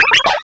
sound / direct_sound_samples / cries / pachirisu.aif